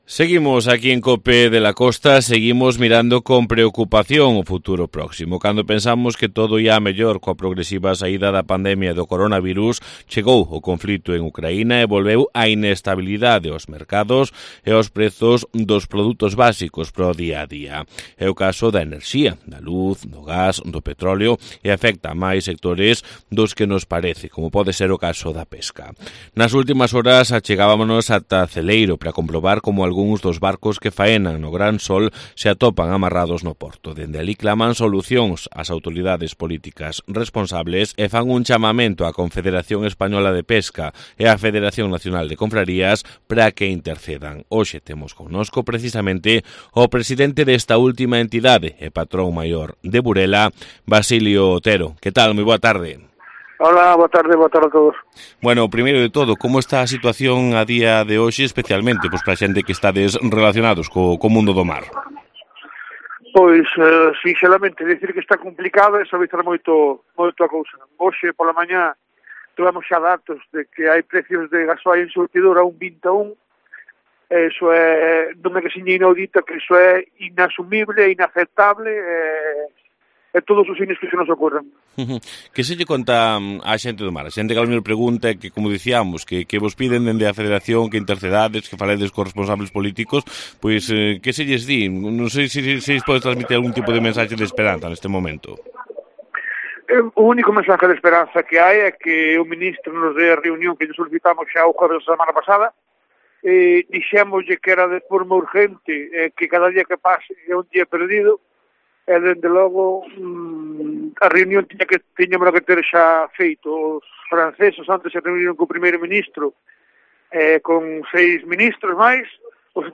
aseguró este miércoles en una entrevista con COPE de la Costa que el precio del combustible -con máximos de hasta 1,21 euros el litro de gasóleo- es actualmente "inasumible" e "inaceptable"